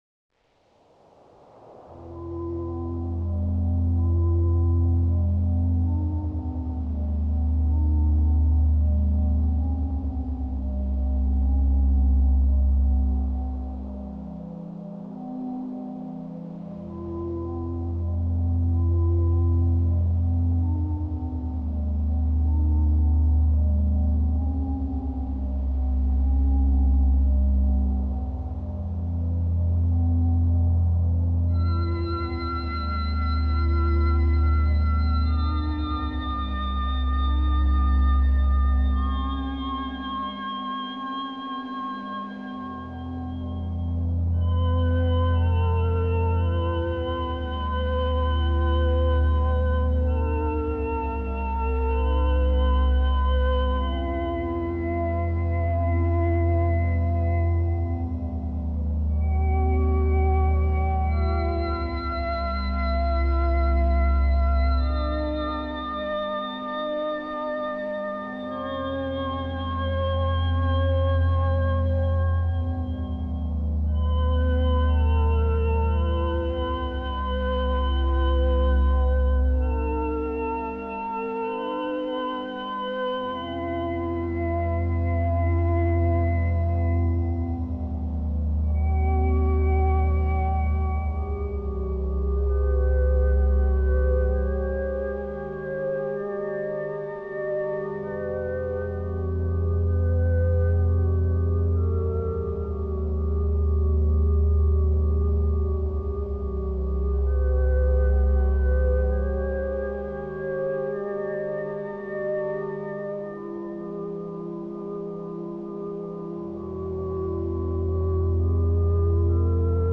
occasional ambient music releases